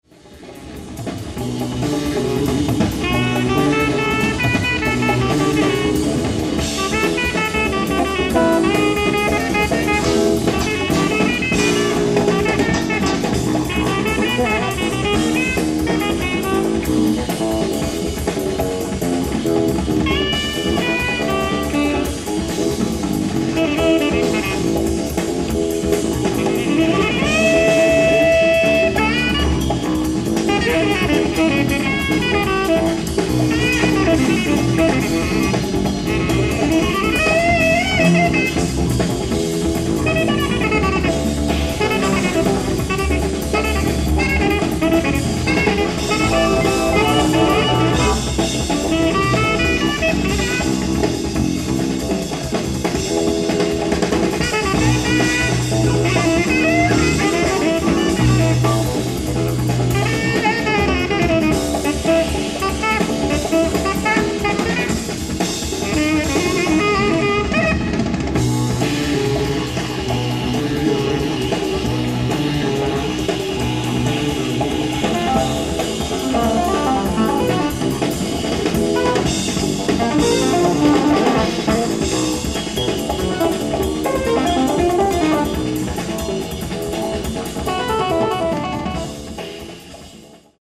ライブ・アット・ユニバーサル・アンフィシアター、ユニバーサル・シティー、カリファルニア 04/15/1983
有名テーパー録音秘蔵マスター音源！！
※試聴用に実際より音質を落としています。